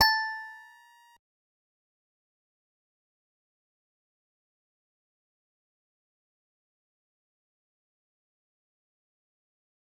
G_Musicbox-A6-mf.wav